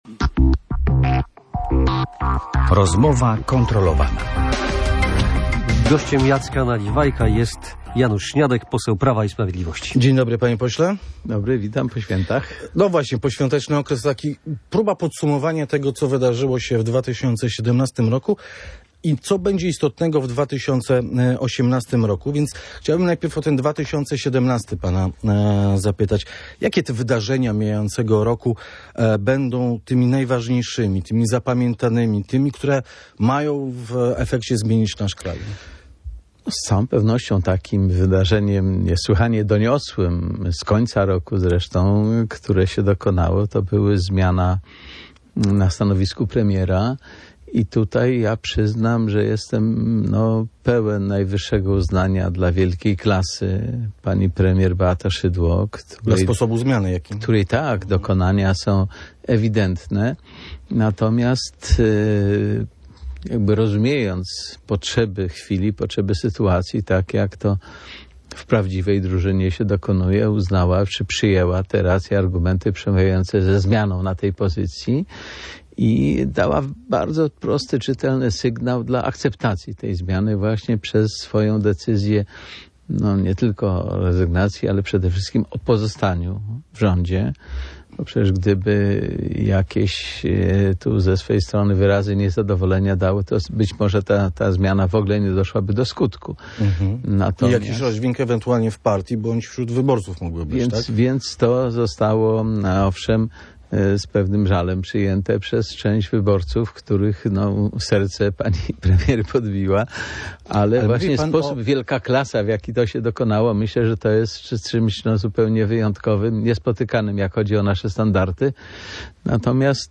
– O tym kto w nich powalczy zdecyduje komitet polityczny PiS – mówił w Radiu Gdańsk poseł Janusz Śniadek.